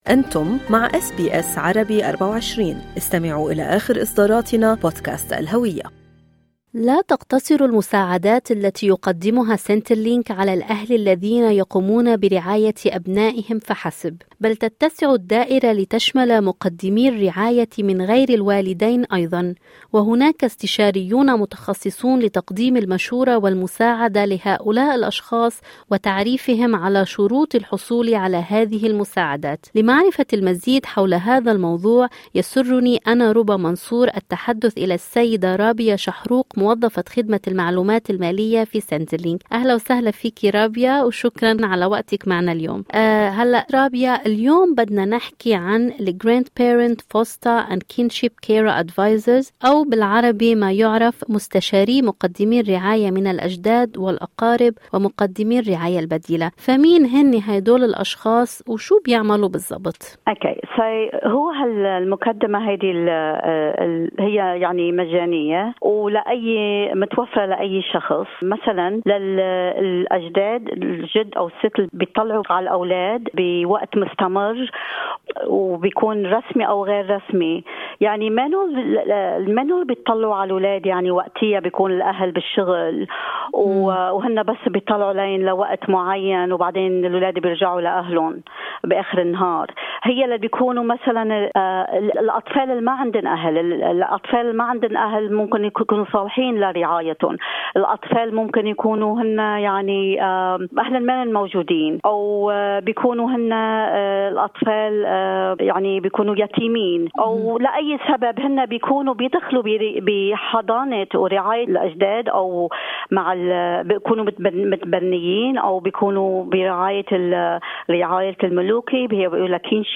في حديث أجرته SBS عربي 24